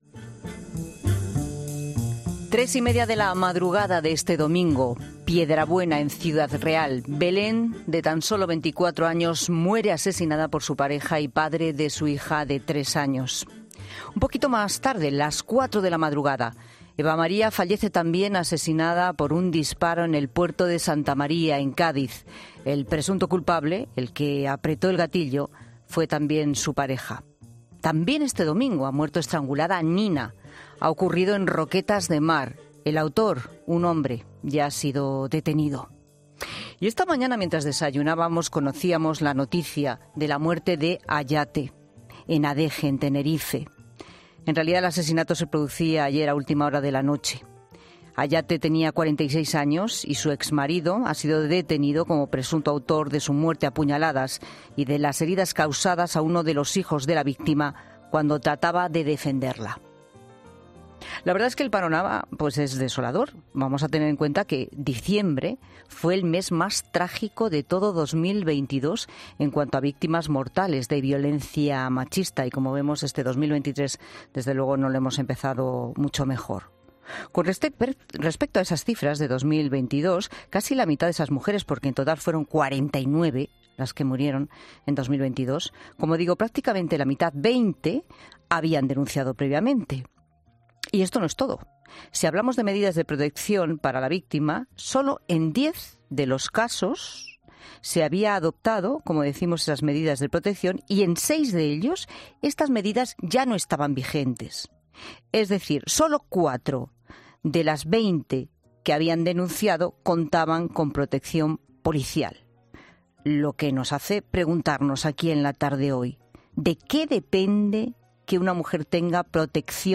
En 'La Tarde' hablamos con una abogada experta en violencia machista tras un inicio negro de 2023, con 4 asesinatos en menos de 24 horas